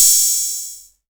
Open Hat [14].wav